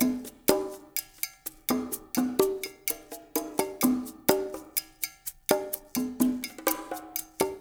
LAY PERC1 -R.wav